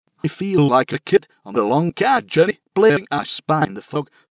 Personalising synthetic voices for individuals with severe speech impairment.